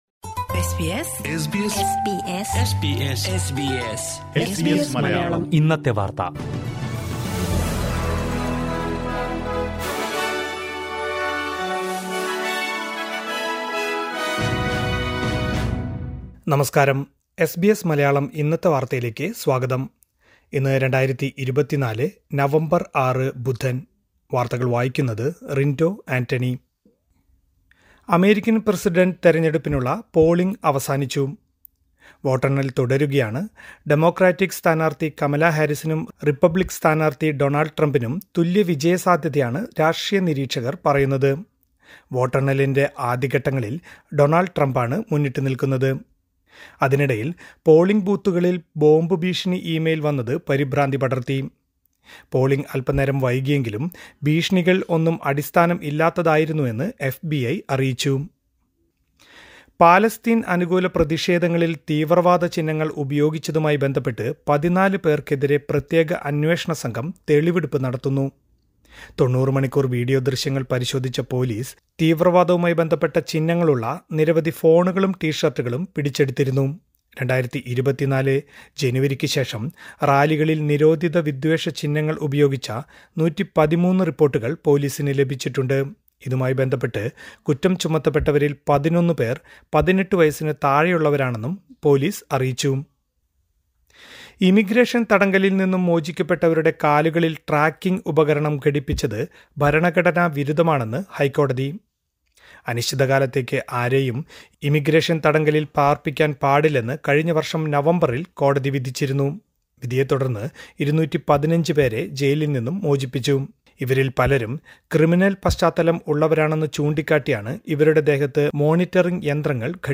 2024 നവംബർ ആറിലെ ഓസ്‌ട്രേലിയിലെ ഏറ്റവും പ്രധാന വാര്‍ത്തകള്‍ കേള്‍ക്കാം...